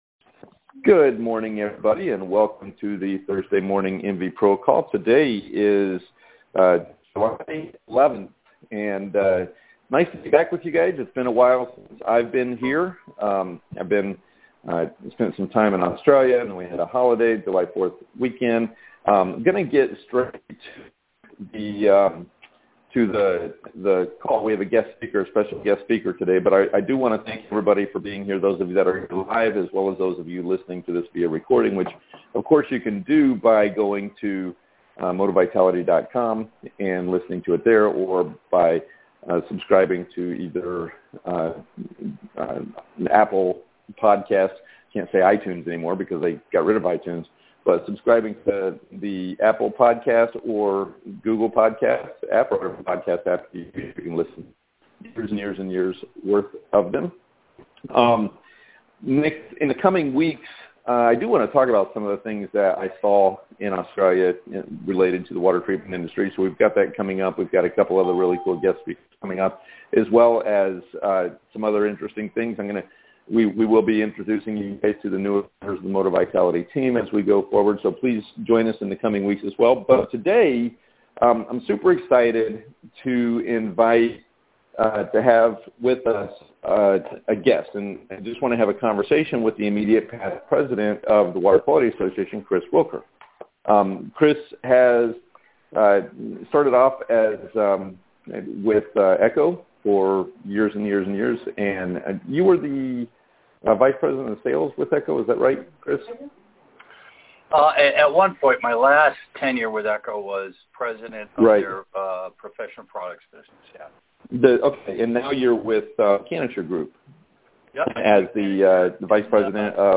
Moti-Vitality holds a weekly MV Professional conference call as a way for the water treatment Sales professionals to get together and learn from each other. We do not discuss specifics about our companies or products but rather the issues that effect our industry and our place in is as sales professionals.